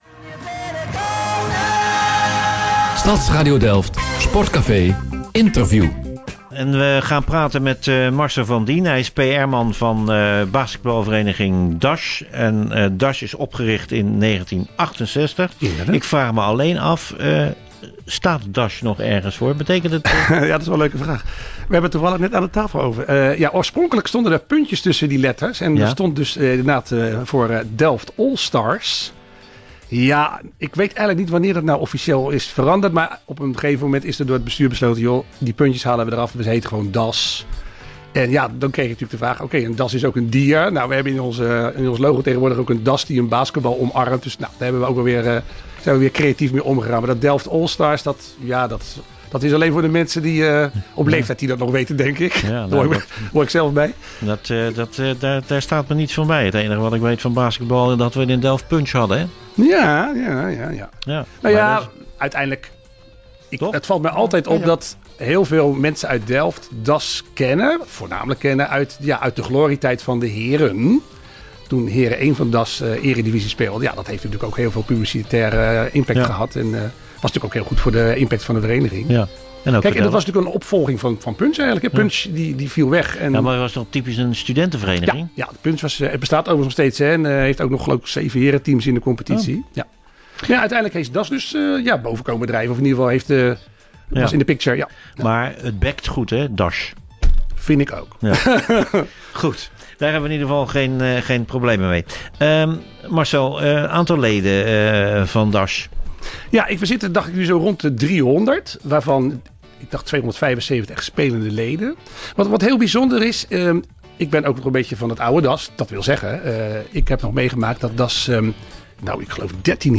Op 25 oktober jl. was DAS uitgenodigd bij het programma "Stadscafé" van de Sportraad Delft, dat elke laatste dinsdag van de maand op Stadsradio Delft wordt uitgezonden.